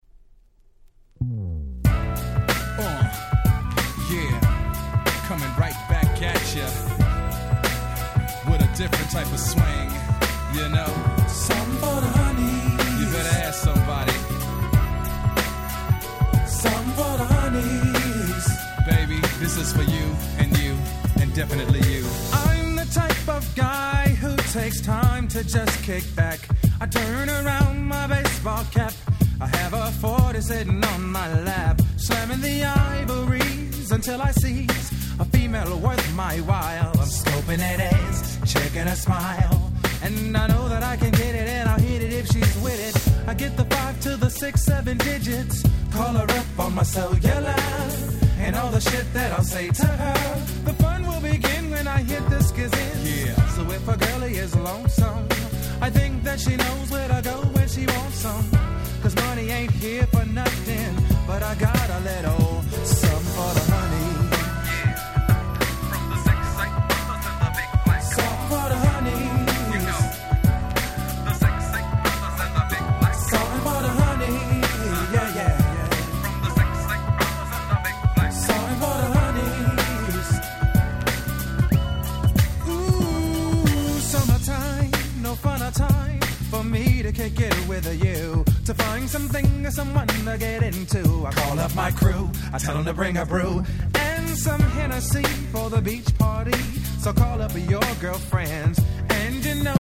95' Super Hit R&B LP !!